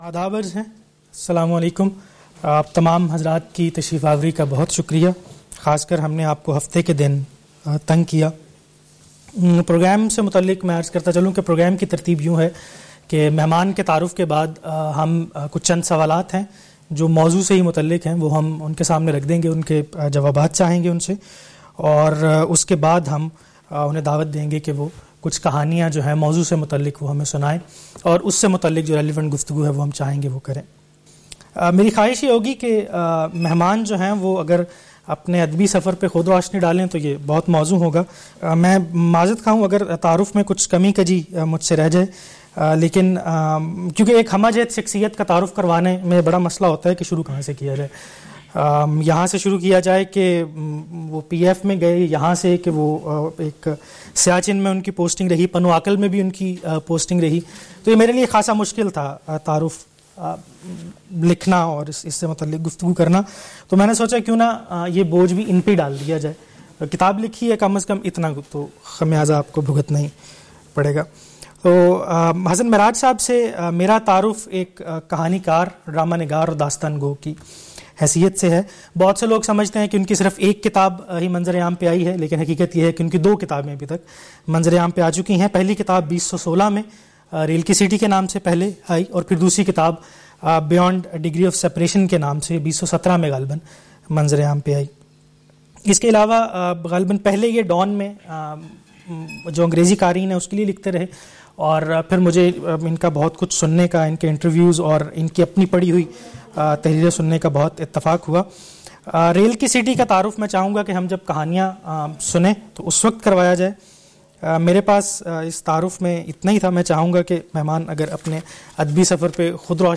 Reading Session